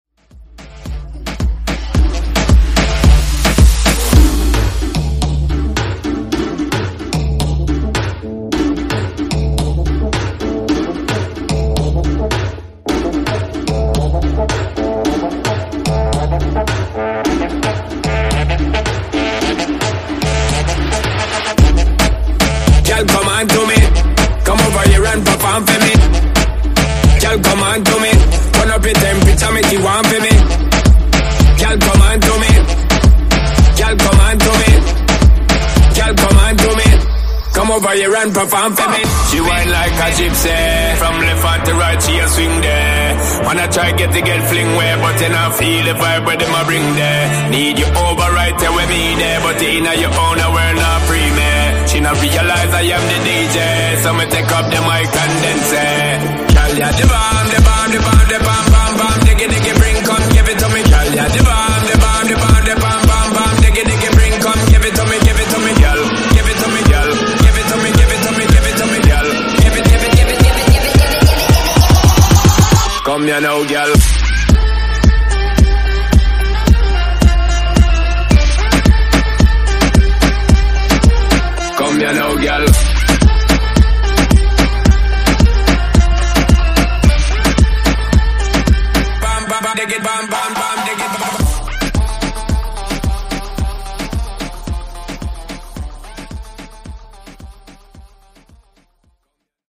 BPM: 110 Time